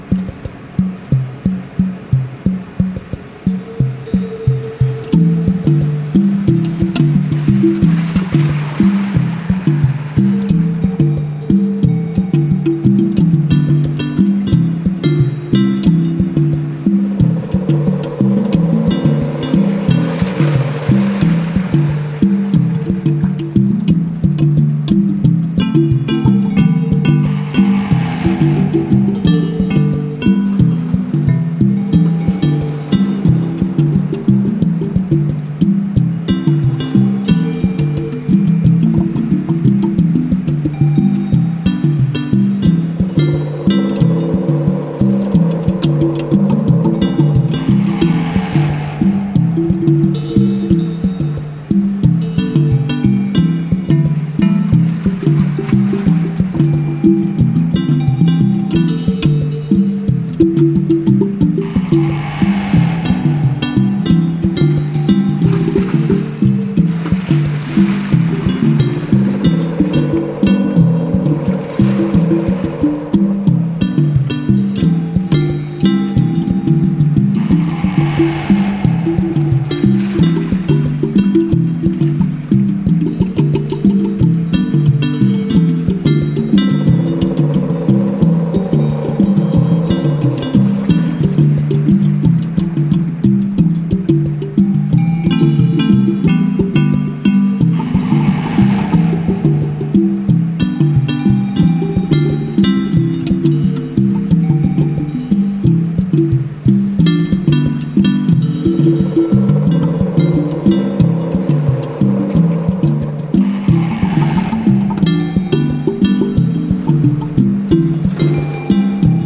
Electronix Indie Ambient